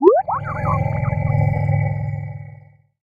bleep.ogg